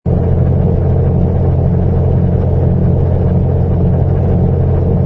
engine_li_freighter_loop.wav